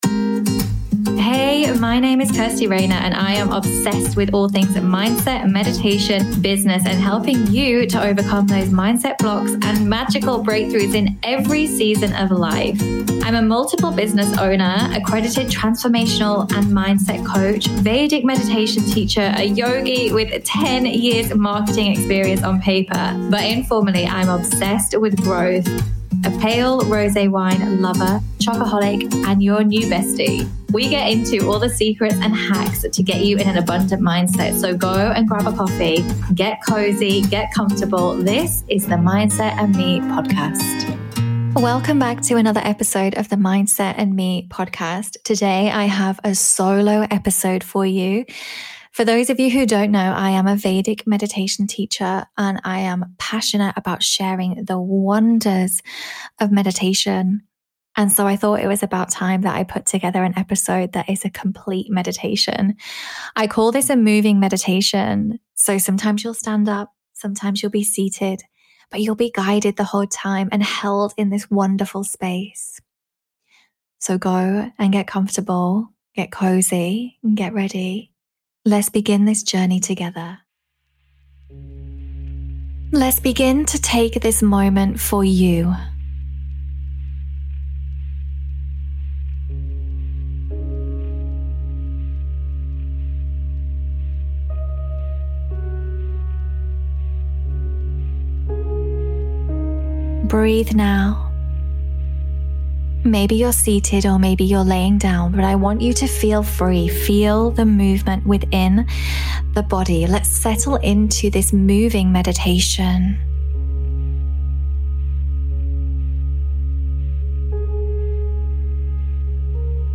20 Minute Moving Meditation